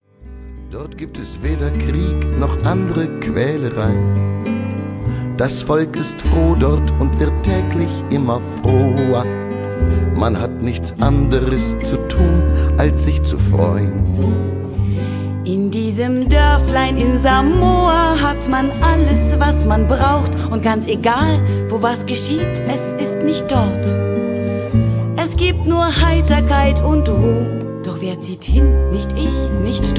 World Music From Berlin